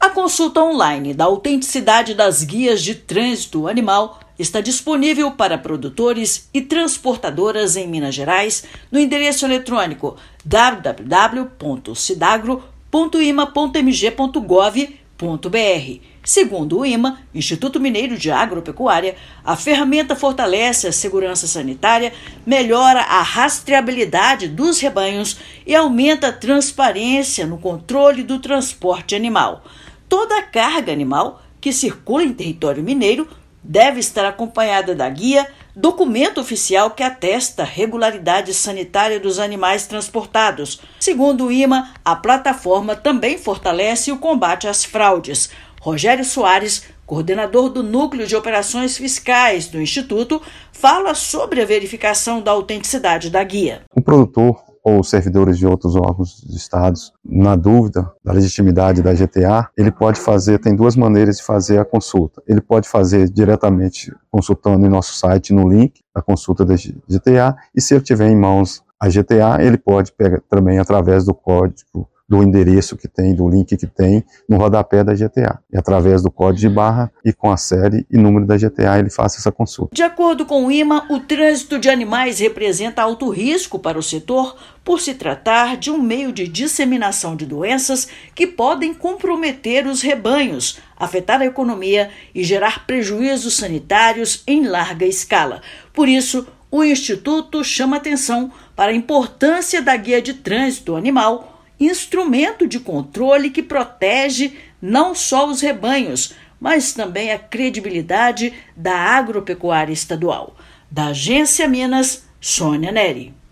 Ferramenta do IMA amplia rastreabilidade dos rebanhos, combate fraudes e garante mais transparência e agilidade. Ouça matéria de rádio.